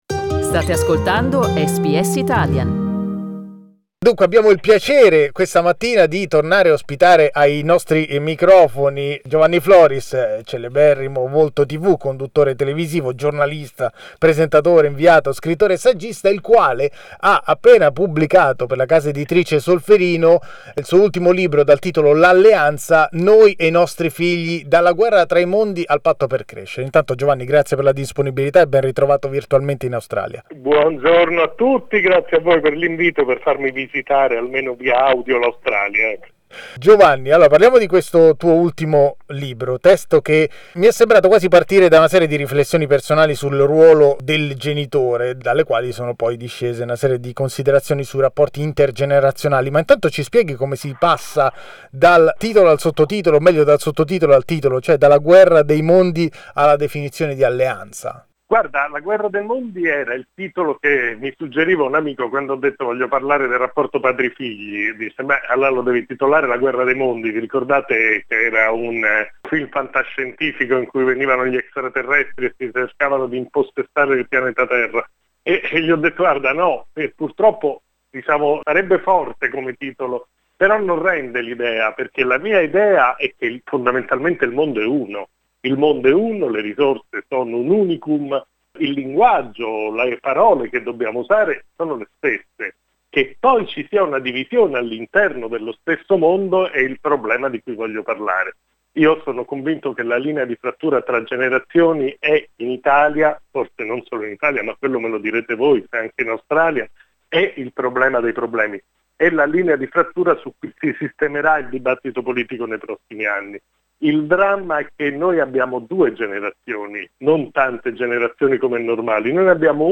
Riascolta qui l'intervista con Giovanni Floris: